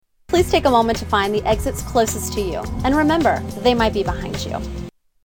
Tags: Travel Delta Flight Flight Attendant Safety tips Deltalina